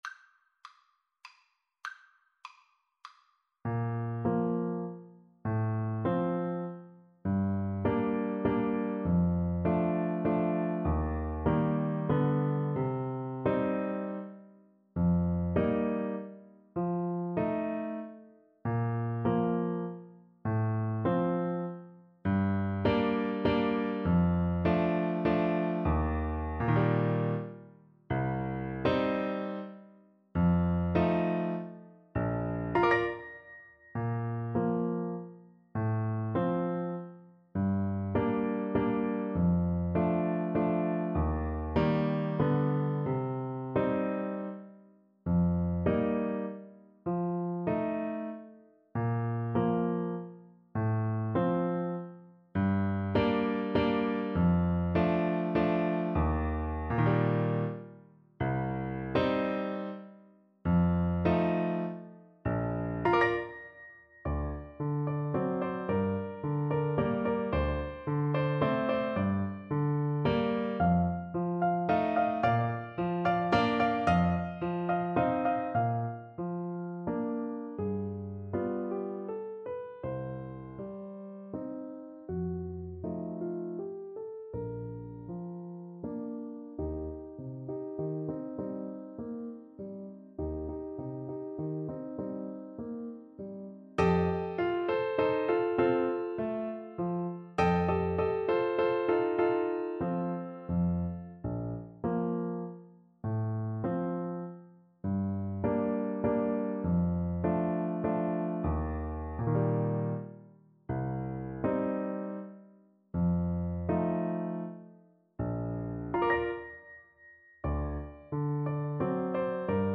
Play (or use space bar on your keyboard) Pause Music Playalong - Piano Accompaniment Playalong Band Accompaniment not yet available transpose reset tempo print settings full screen
Bb major (Sounding Pitch) C major (Trumpet in Bb) (View more Bb major Music for Trumpet )
3/4 (View more 3/4 Music)
~ = 100 Tranquillamente
Classical (View more Classical Trumpet Music)